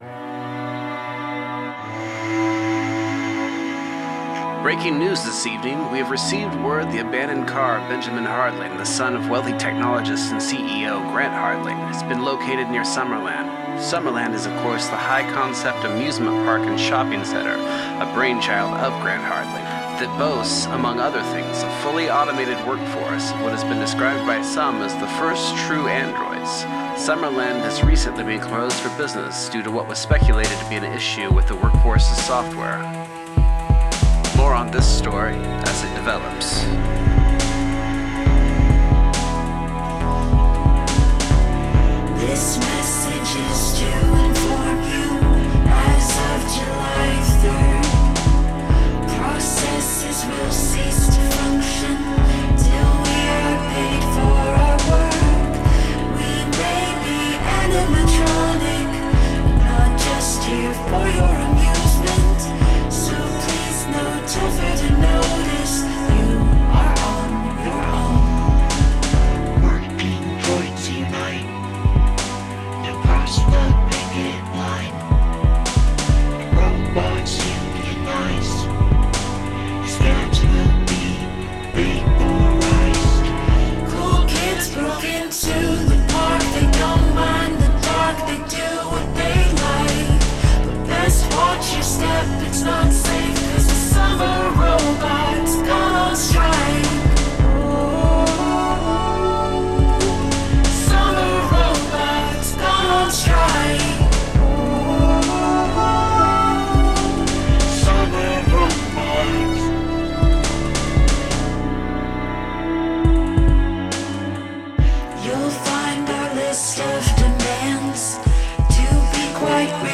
There are NO guitars. There is NO piano.